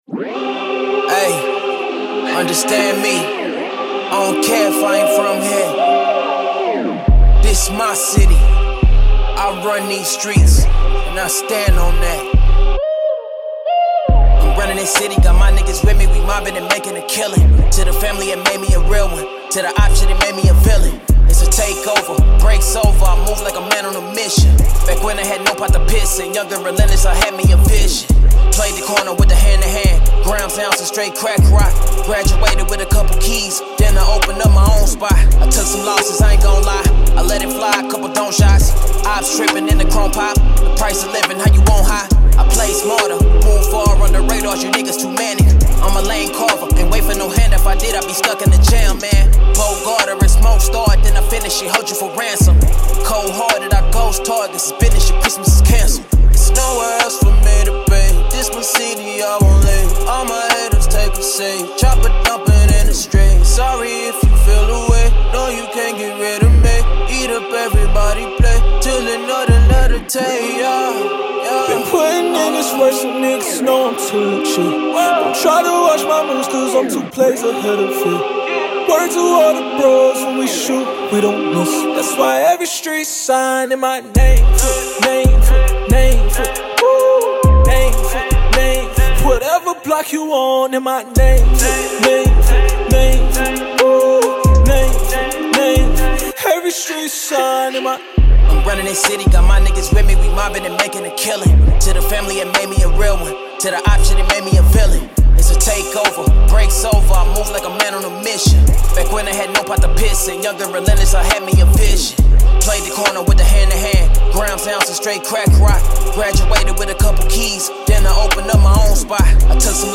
Hip Hop, Rap
F Minor
Anthemic, motivational track